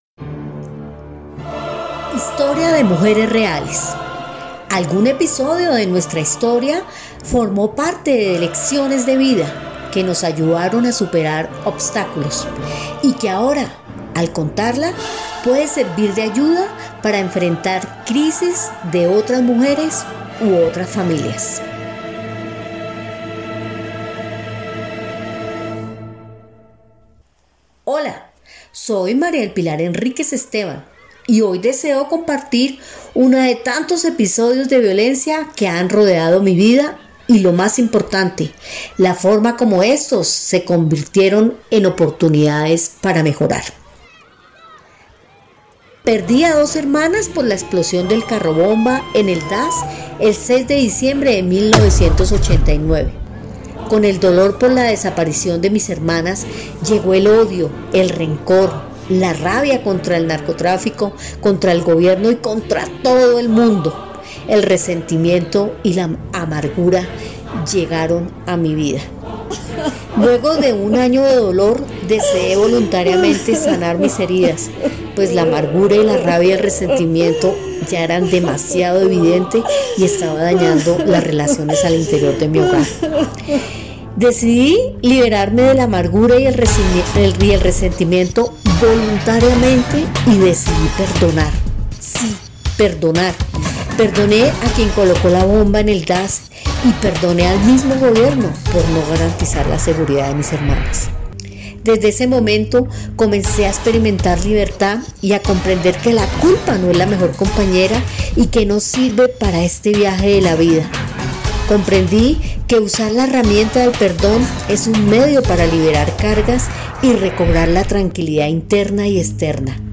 Podcast desarrollado en el taller de radio, que se ejecutó en el primer semestre del año 2020, bajo el marco del premio Daniel Samper Ortega, otorgado a la Biblioteca Pública Carlos E. Restrepo en el 2018. Contó con la participación intergeneracional de usuarios de la mencionada biblioteca. ¿Cómo superar una situación difícil de la vida, causada por otra persona?
Producción radial, Perdón